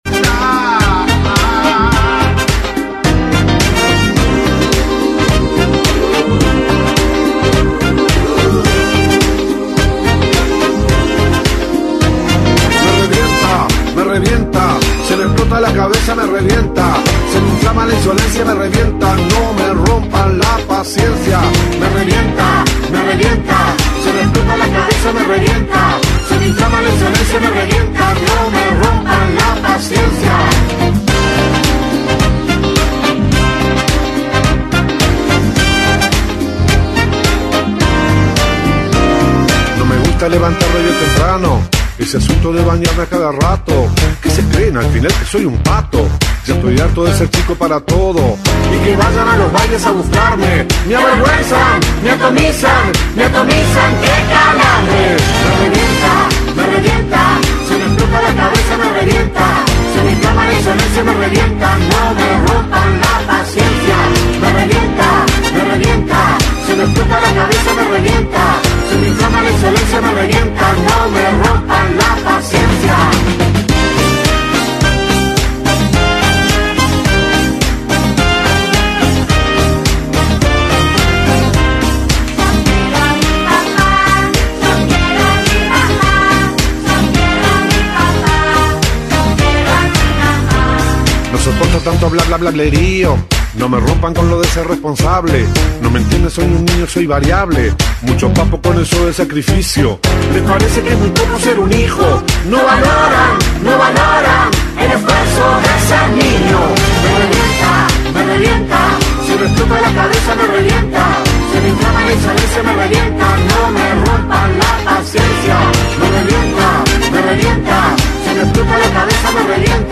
El músico visitó Rompkbzas para contar los detalles de su show y hablar de la vida.